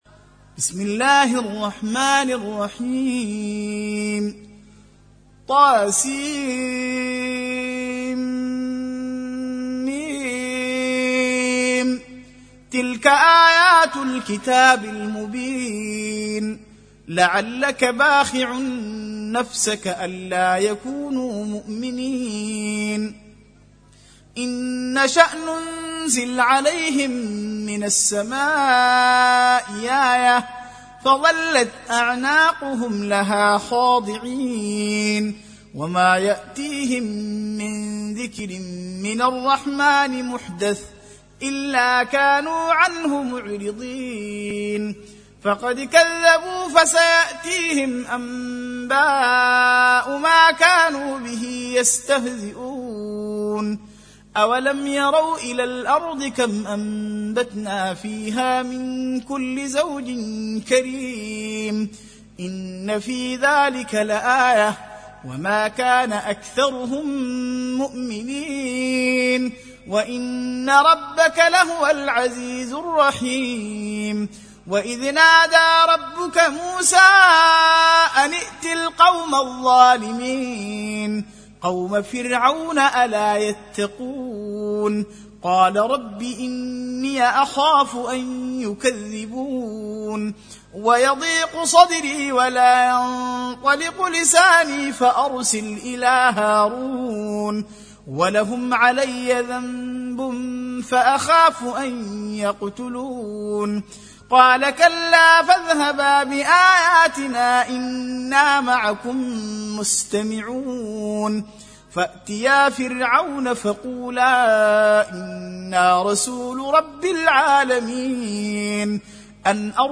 Surah Repeating تكرار السورة Download Surah حمّل السورة Reciting Murattalah Audio for 26. Surah Ash-Shu'ar�' سورة الشعراء N.B *Surah Includes Al-Basmalah Reciters Sequents تتابع التلاوات Reciters Repeats تكرار التلاوات